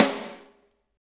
Index of /90_sSampleCDs/Roland L-CD701/SNR_Snares 2/SNR_Sn Modules 2
SNR GRAB 00L.wav